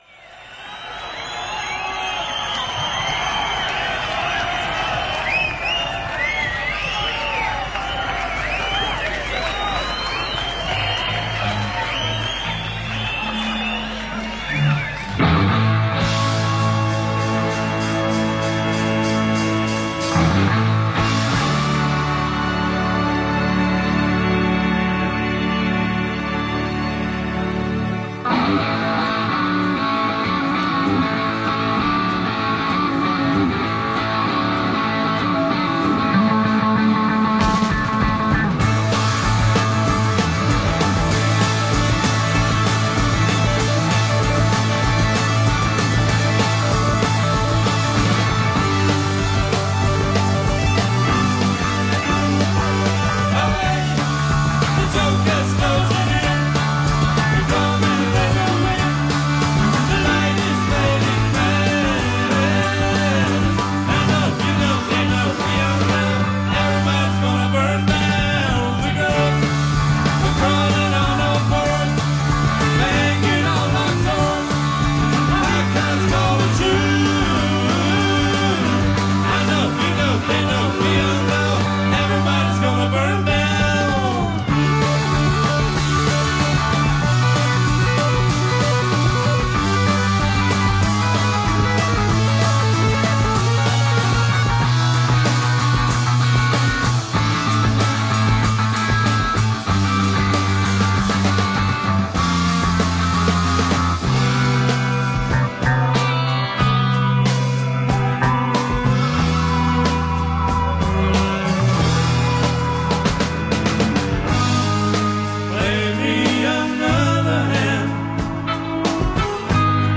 Guildhall, Portsmouth – Golders Green Hippodrome